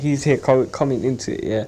k-backing
Rather than a mildly retracted k in words such as car, come, caught, many younger inner-London speakers have a very retracted plosive, perhaps even a uvular q.
Listen to clips of a young Anglo (= ethnically white) speaker pronouncing the phrases